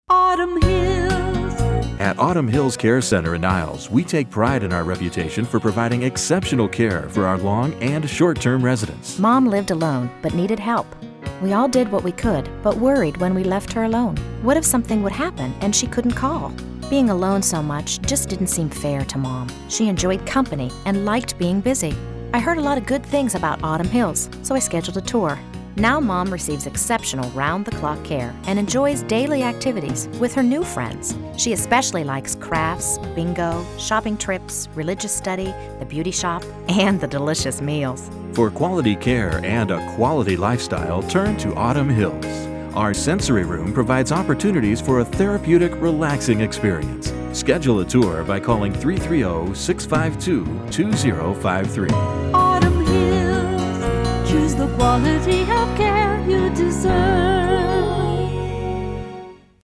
Radio Samples [.mp3]